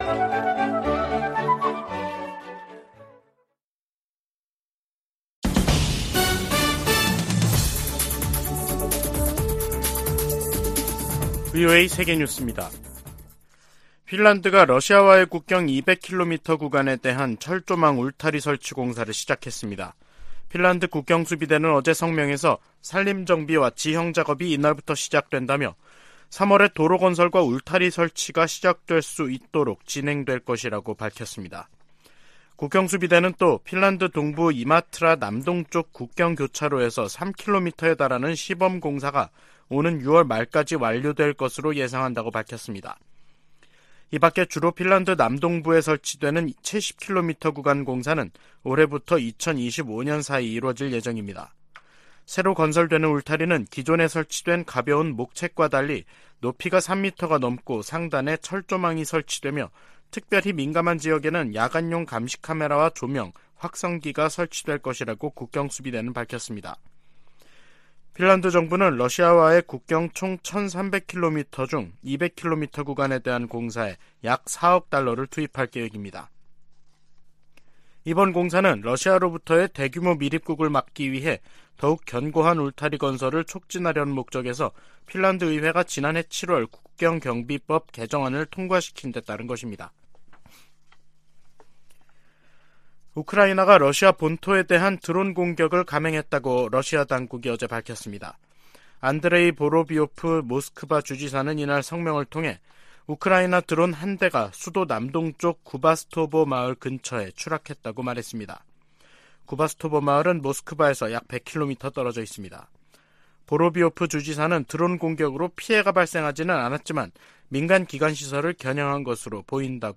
VOA 한국어 간판 뉴스 프로그램 '뉴스 투데이', 2023년 3월 1일 3부 방송입니다. 미국과 한국은 확장억제수단 운용연습(DSC TTX)을 성공적으로 진행했다며, 대북 확장억제 강화 필요성에 동의했다고 미 국방부가 밝혔습니다. 유엔 군축회의에서 한국 등이 북한의 핵과 탄도미사일 개발을 심각한 안보 도전이라고 비판했습니다. 윤석열 한국 대통령은 3.1절 기념사에서 일본은 협력 파트너로 변했다며 북 핵 대응 미한일 공조의 중요성을 강조했습니다.